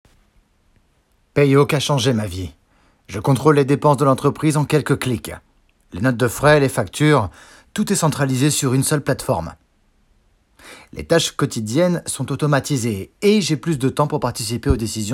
Voix off
J'ai une voix médium/grave.
27 - 69 ans - Baryton